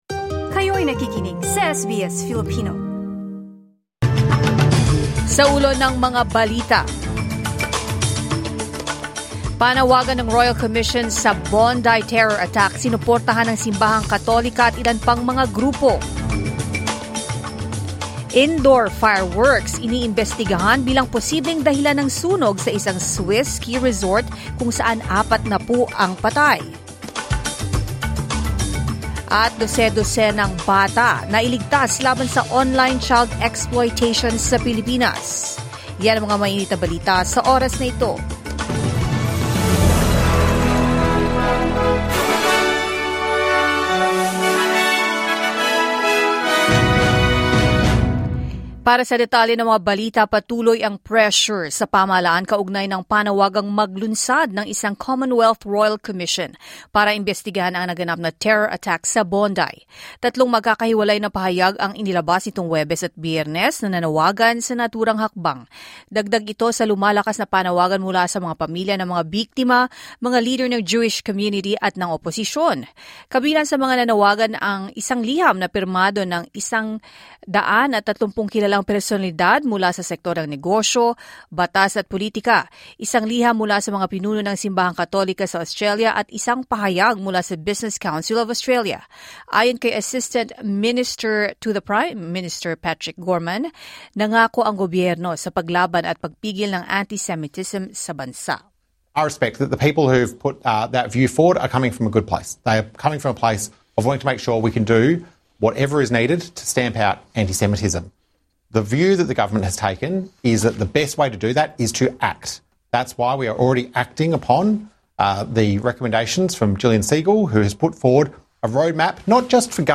SBS News in Filipino, Saturday 3 January 2026